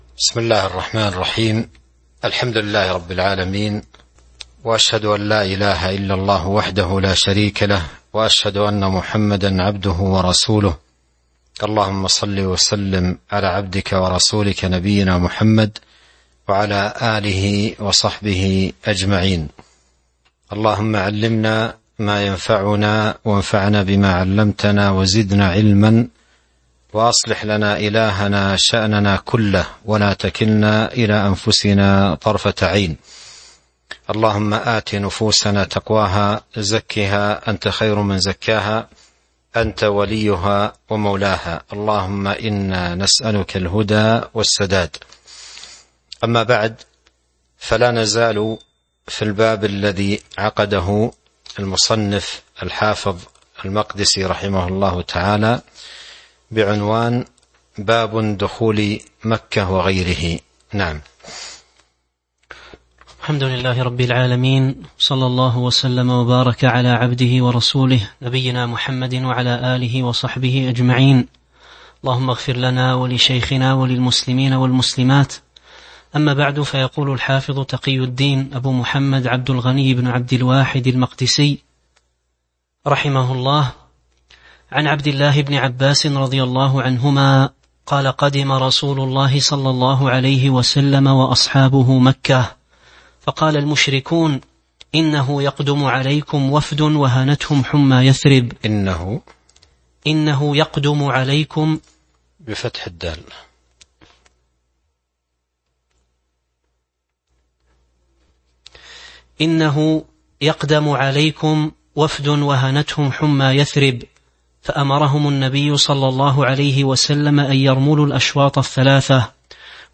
تاريخ النشر ٢٦ ذو القعدة ١٤٤٢ هـ المكان: المسجد النبوي الشيخ